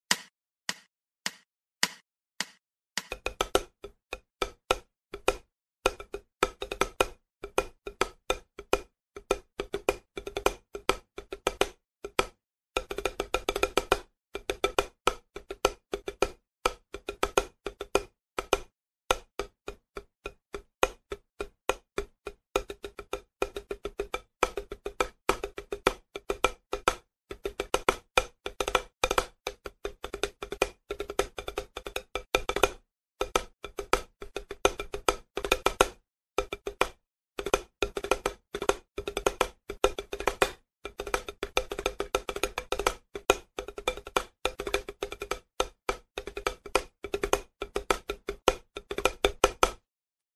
Все этюды записаны на педе для большей разборчивости и возможно помогут тем кто занимается по указанной книге самостоятельно.
Этюд №4 с использованием рудимента Three Stroke Roll.
Размер 3/4, темп 104
Сыгран без повторов, с проходящими вольтами.